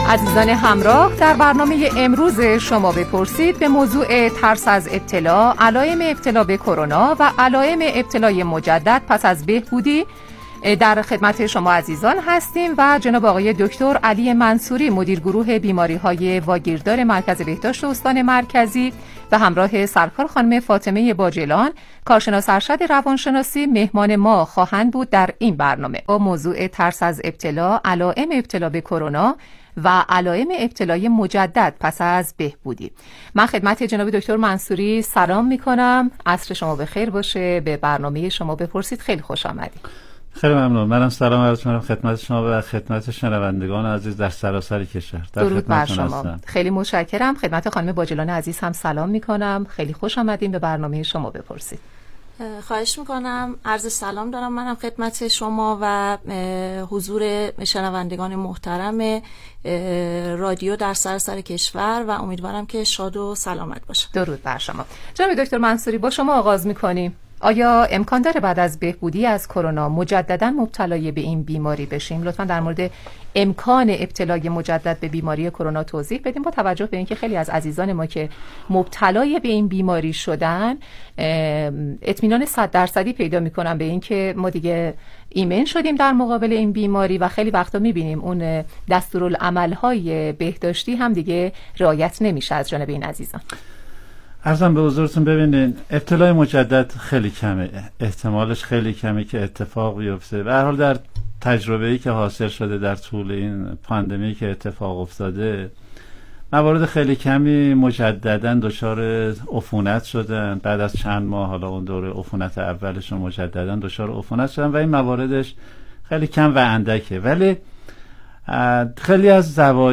برنامه رادیویی "شما بپرسید"